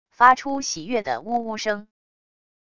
发出喜悦的呜呜声wav音频